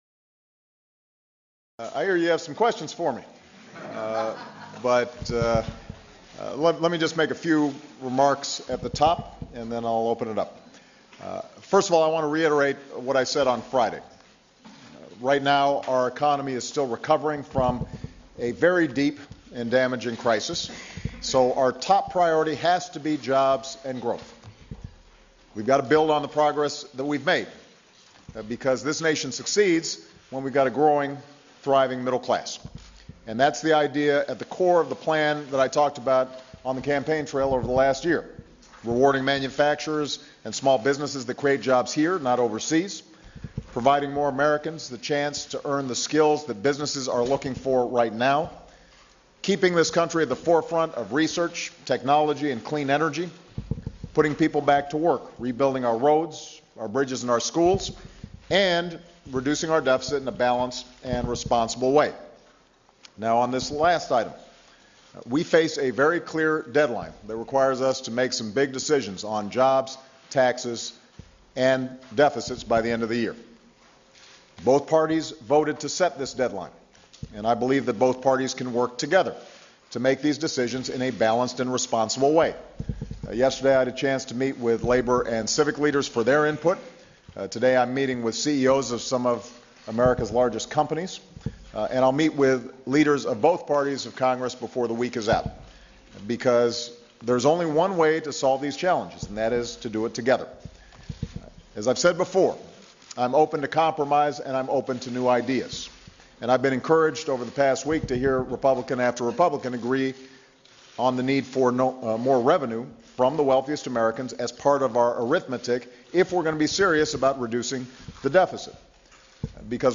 President Obama speaks to reporters and answers questions in his first formal news conference since his re-election
Broadcast on C-SPAN, Nov. 14, 2012.